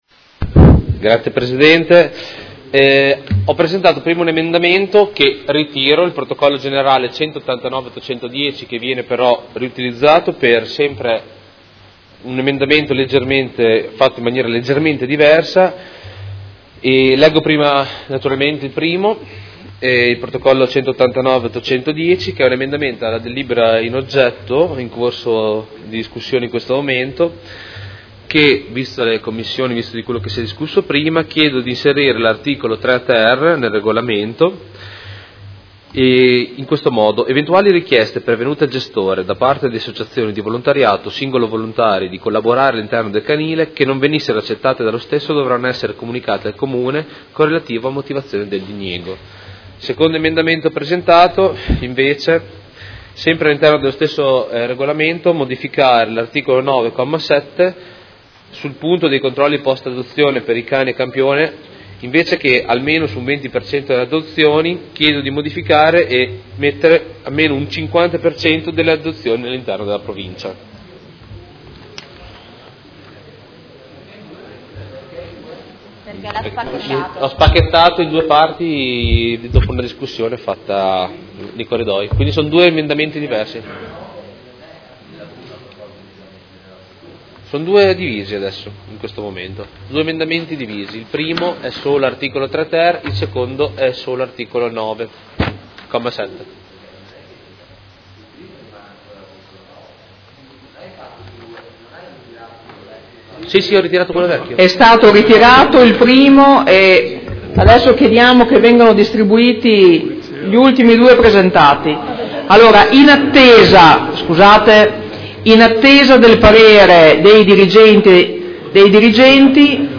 Seduta del 14/12/2017 Dibattito.